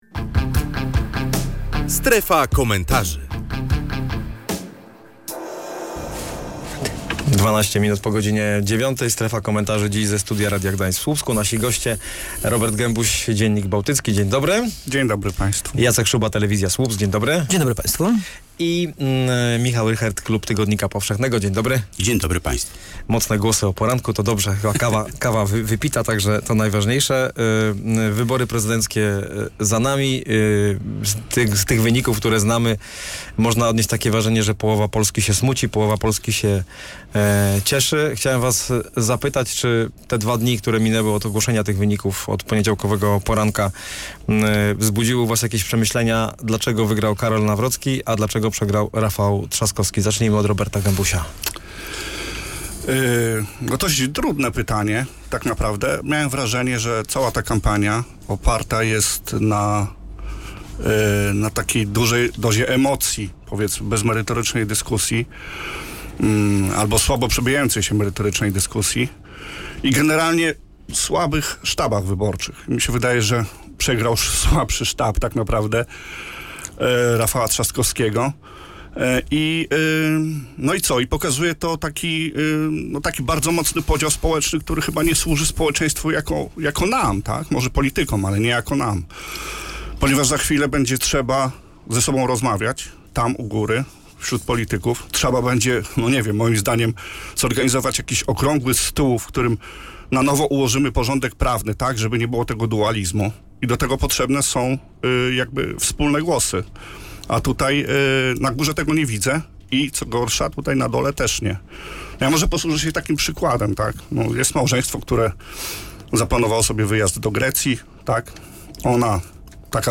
słupskiego studia komentowali